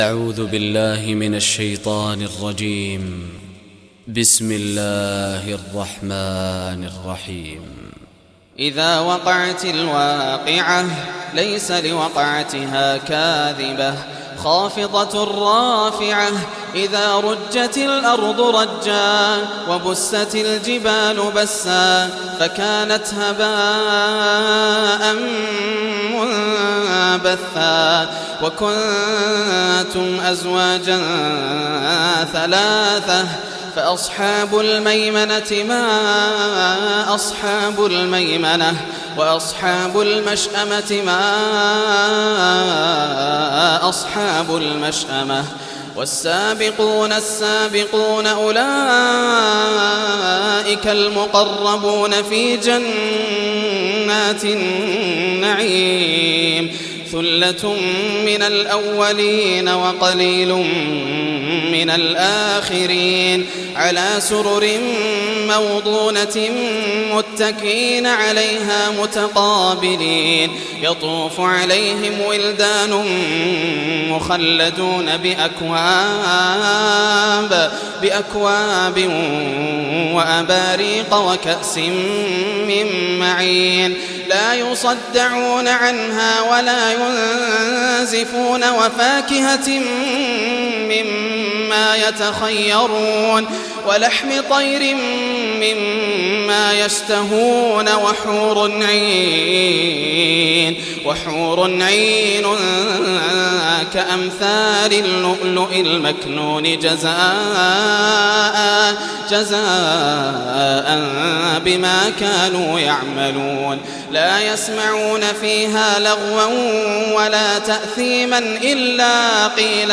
سورة الواقعة 1424 > الإصدارات > المزيد - تلاوات ياسر الدوسري